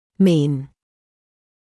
[miːn][миːн]иметь в виду; средний